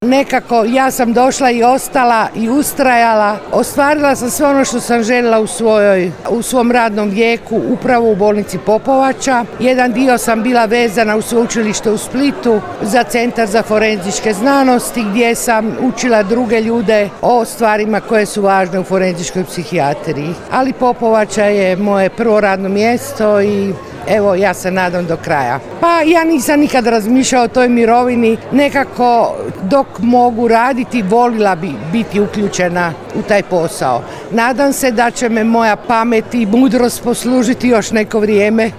U povodu obilježavanja 90 godina Neuropsihijatrijske bolnice „dr. Ivan Barbot” Popovača u petak, 22. studenog 2024. godine, u Domu kulture u Popovači održana je prigodna svečanost.